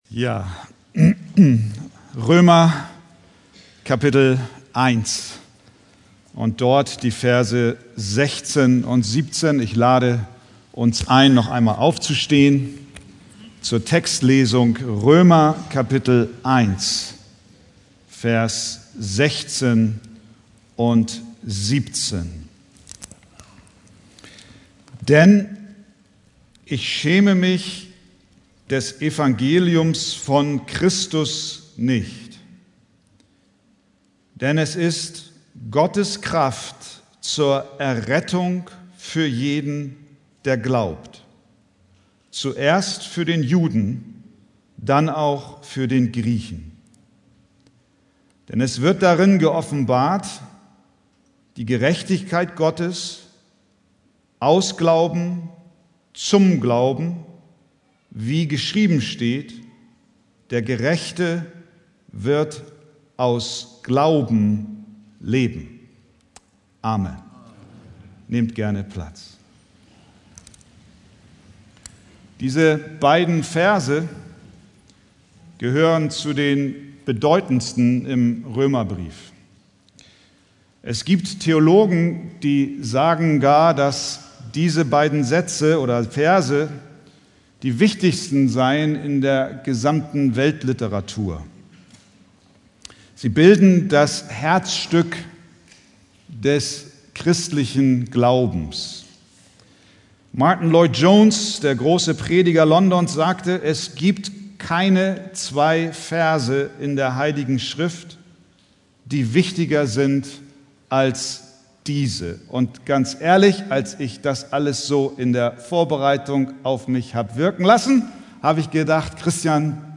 Predigttext: Römer 1,16-17